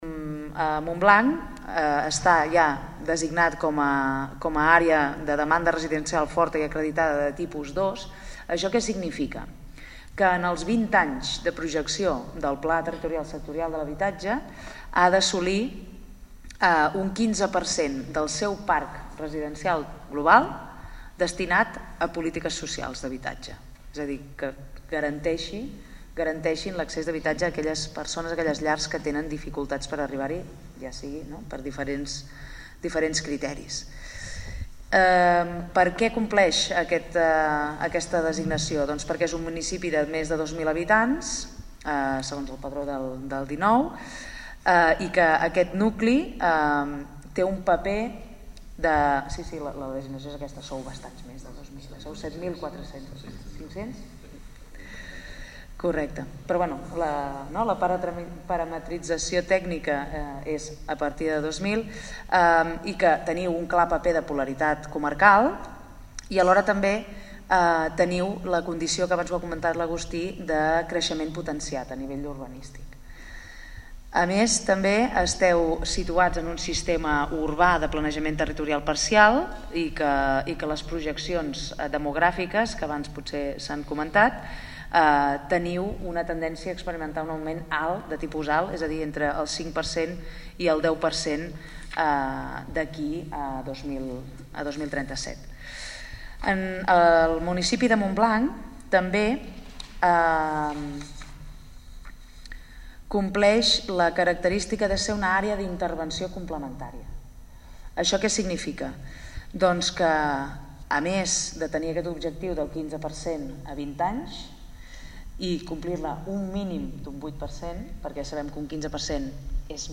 Esquerra Montblanc va debatre dissabte 18 de març, en un acte celebrat a l’antic hospital de Santa Magdalena, sobre urbanisme i habitatge. I ho va fer amb les intervencions de Francesc Sutrias, secretari de territori, urbanisme i agenda urbana de la Generalitat; Marina Berasategui, secretària d’habitatge de la Generalitat i Agustí Serra, secretari general d’urbanisme de la Generalitat.